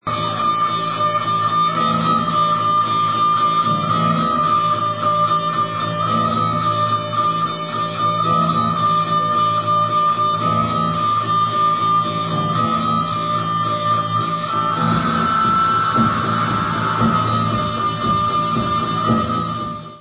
Dance/Techno